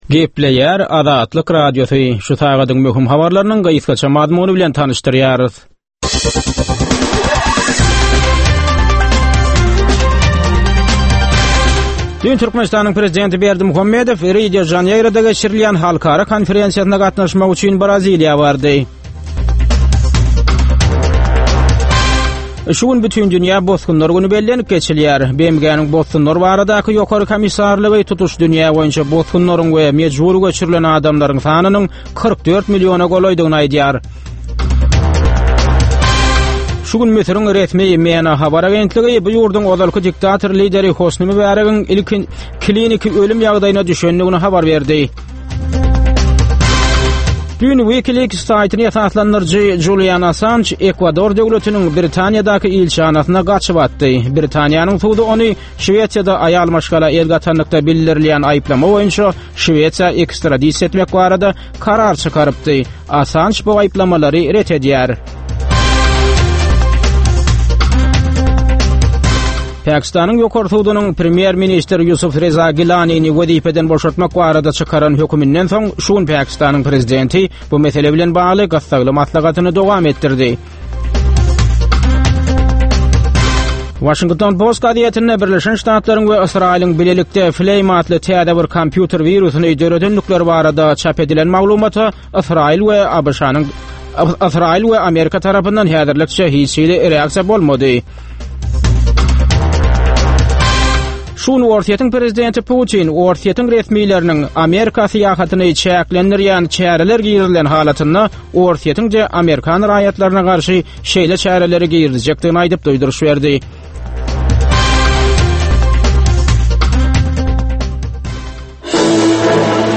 Türkmenistandaky we halkara arenasyndaky möhüm wakalar we meseleler barada ýörite informasion-analitiki programma. Bu programmada soňky möhüm wakalar we meseleler barada analizler, synlar, söhbetdeşlikler, kommentariýalar we diskussiýalar berilýär.